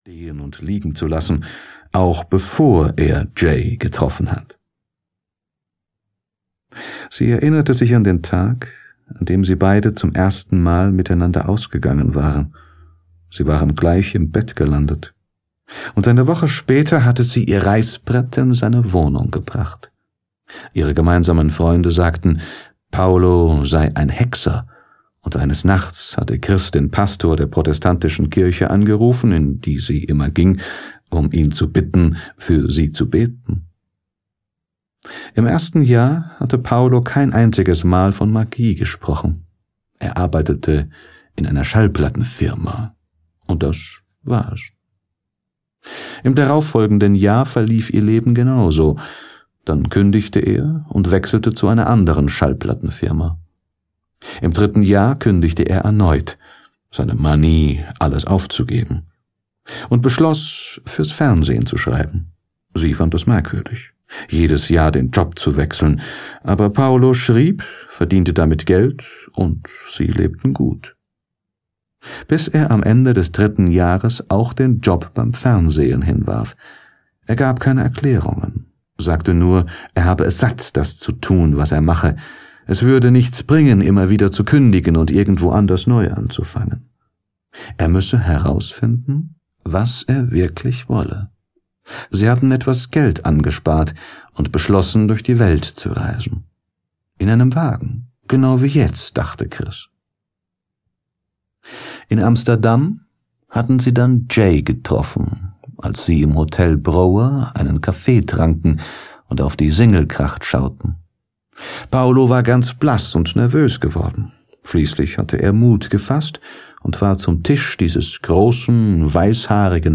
Schutzengel - Paulo Coelho - Hörbuch